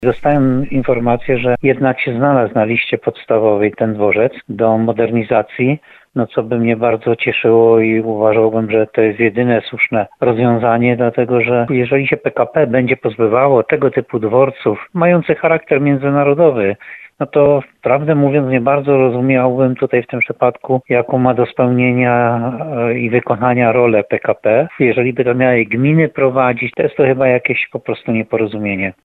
– Okazuje się jednak, że sytuacja się zmieniła – mówi Jan Golba, burmistrz Muszyny.